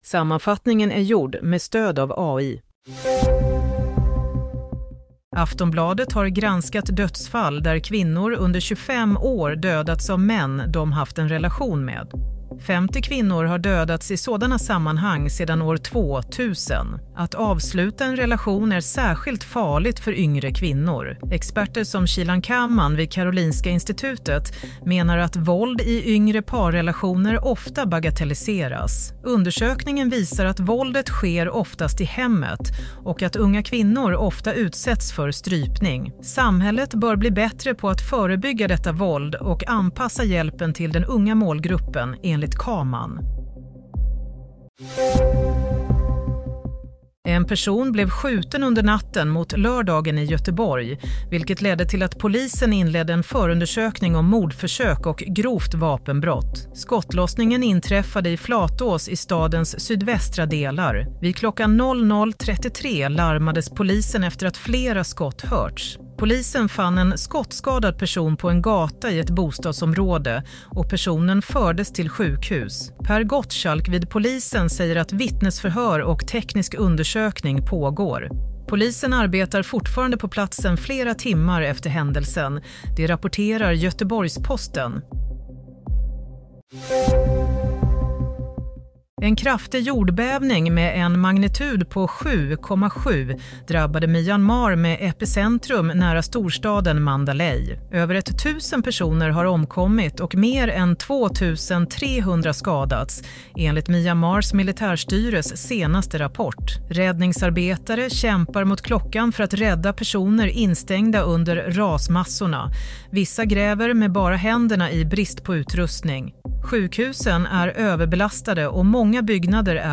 Nyhetssammanfattning - 29 mars 07:30
Sammanfattningen av följande nyheter är gjord med stöd av AI.